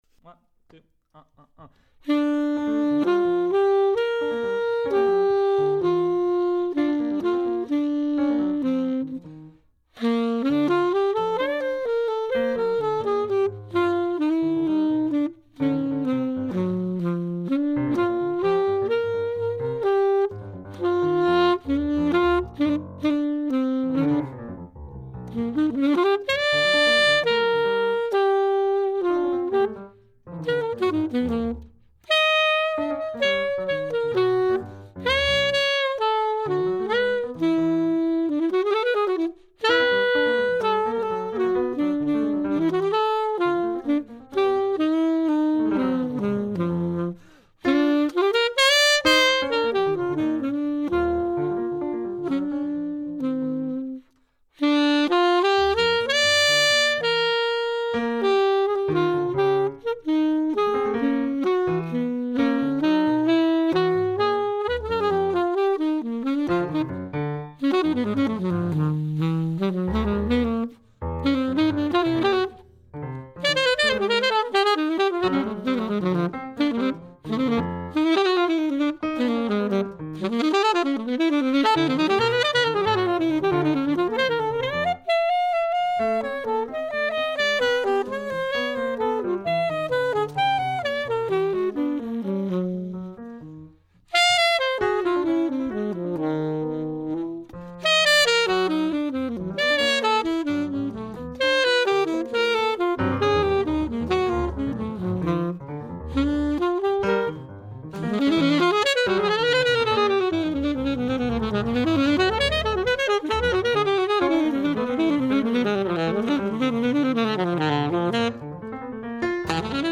sax
piano